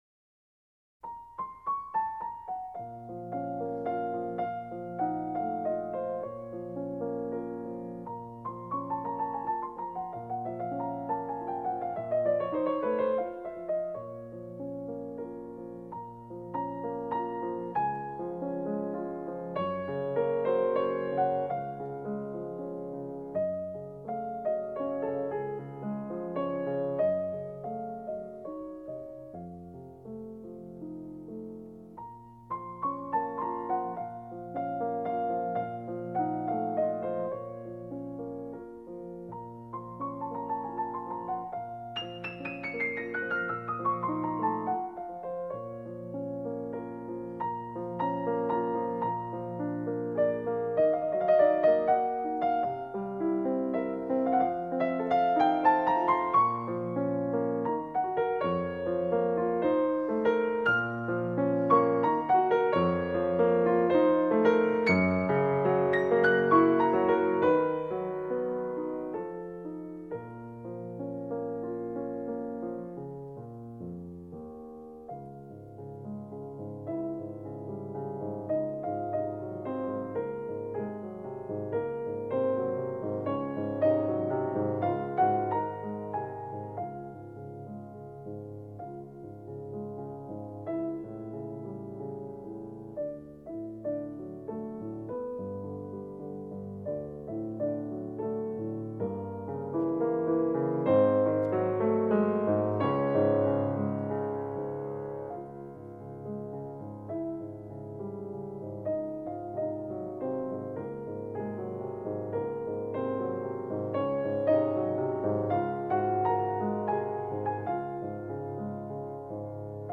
降B小调，OP9.1，作于1830-1831年，献给玛丽.普勒菲夫人，极慢板，充满夜的优美。